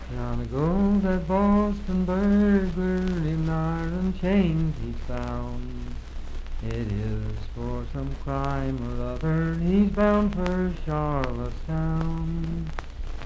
Unaccompanied vocal music
Voice (sung)
Pocahontas County (W. Va.), Marlinton (W. Va.)